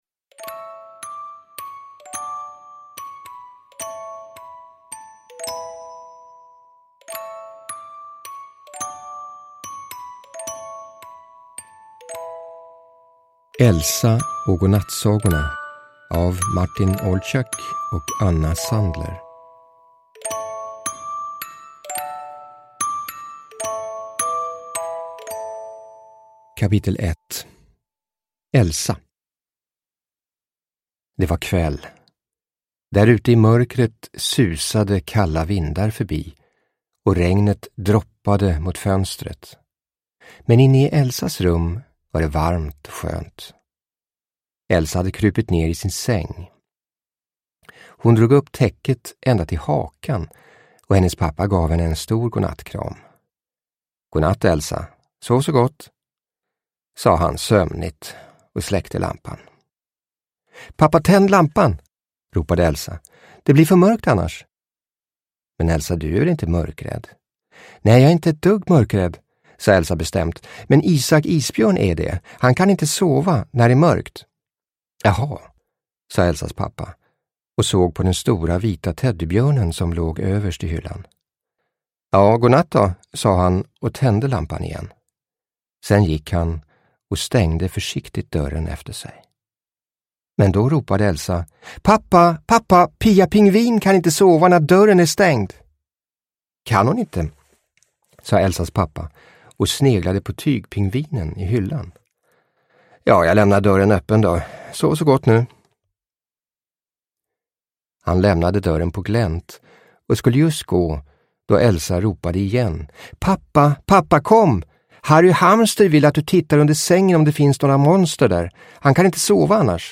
Elsa och godnattsagorna – Ljudbok – Laddas ner